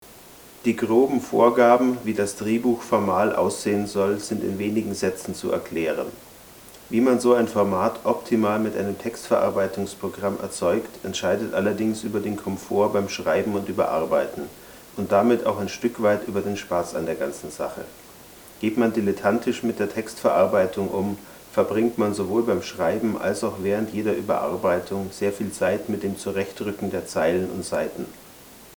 Ich denke, die Beispiele bedürfen, was Klang und Rauschen betrifft, keines weiteren Kommentars.
Selbst das t.bone EM9600 rauscht weniger - und das will was heißen.